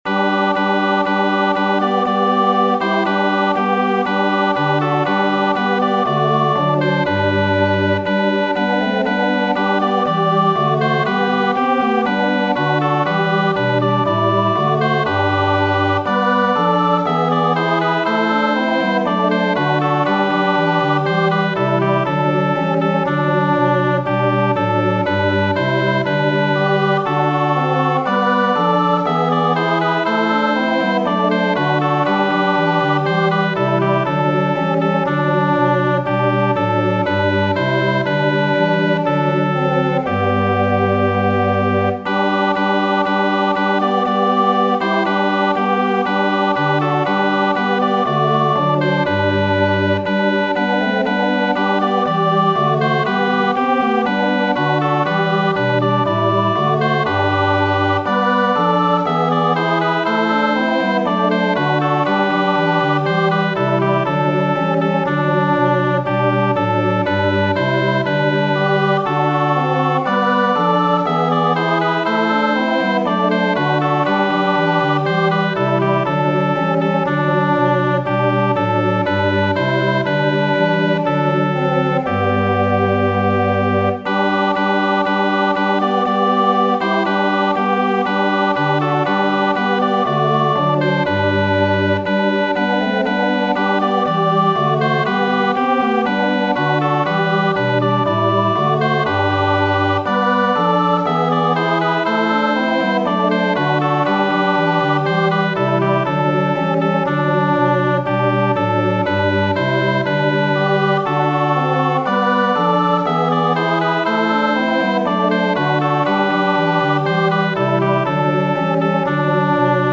Muziko:
Anĝelojn mi vidis en alto, kristnaska kanto de Haendel.